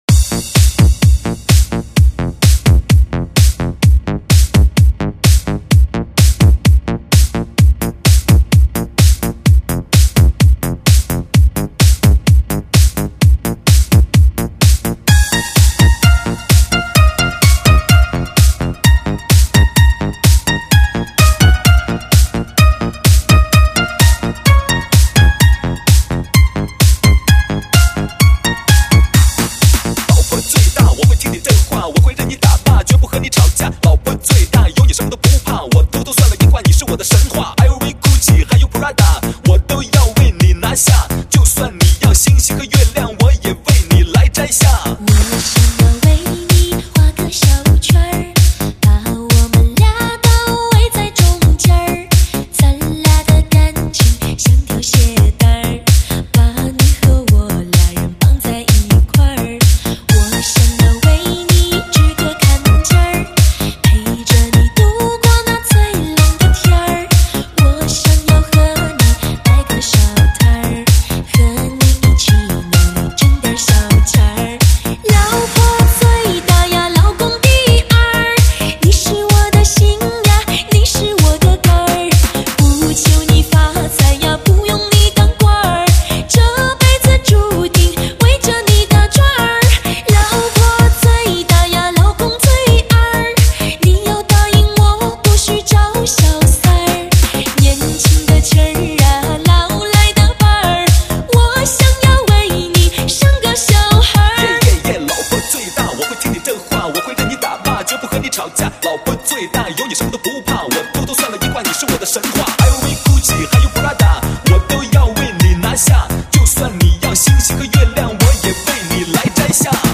流行的时尚节奏 中文舞曲
唱片类型：电音炫音
流行的时尚节奏与舞曲特有的鲜明节奏，让人有一种说不出的畅快之感，如果你厌倦了平淡乏味的音乐，
轻松的中文舞曲。。。很好听。